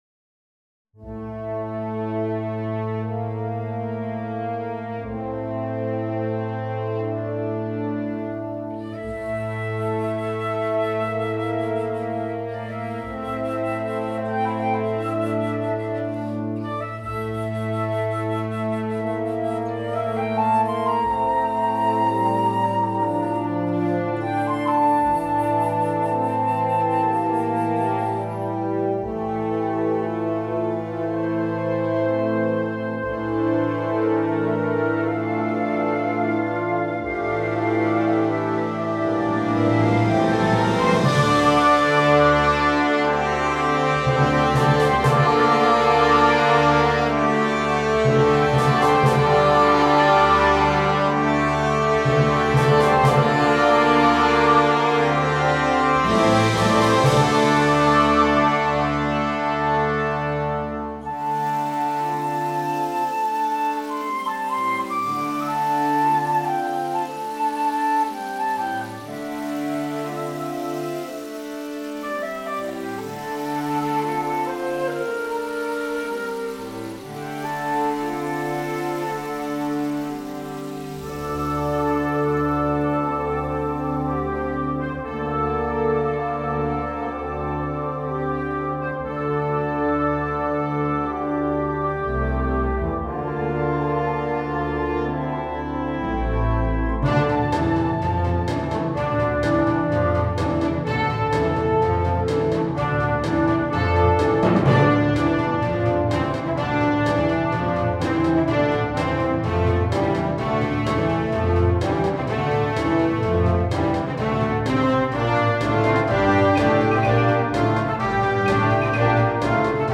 Partitions pour orchestre d'harmonie.
• View File Orchestre d'Harmonie
Elle est divisée en quatre sections étroitement liées.